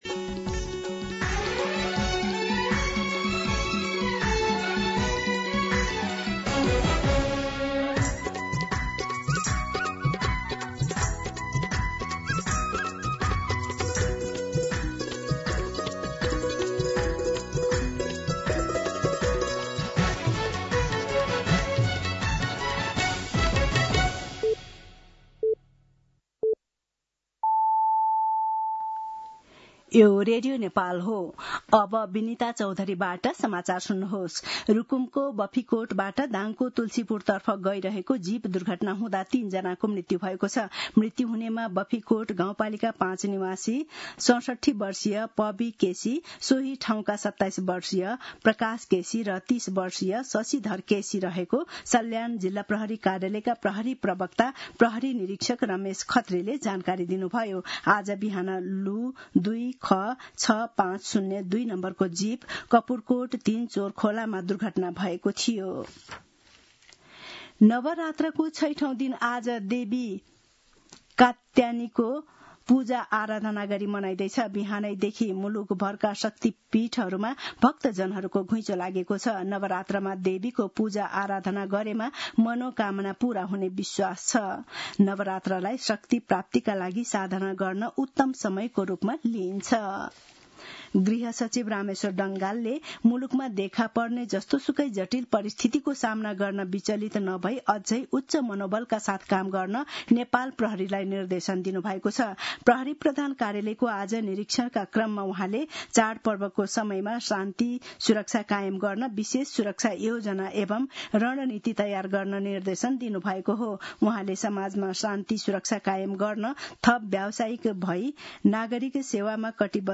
दिउँसो ४ बजेको नेपाली समाचार : १२ असोज , २०८२